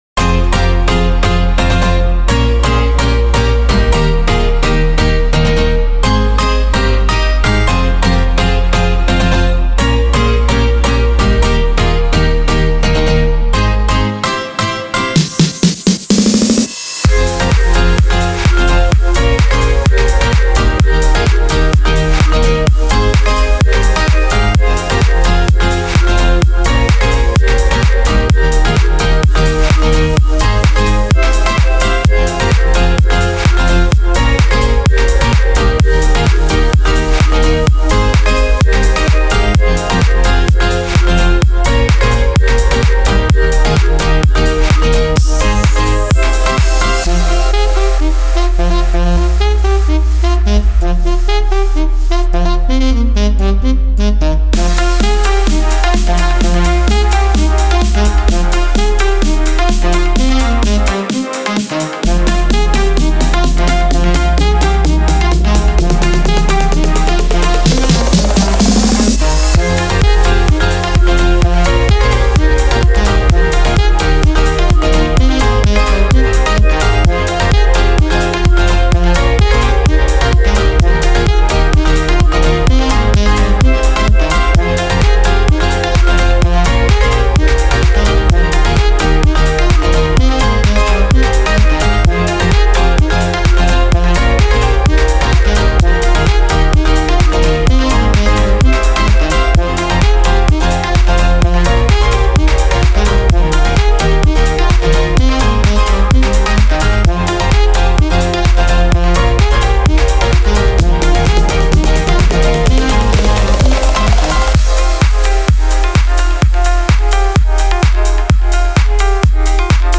Drums, FX, Fills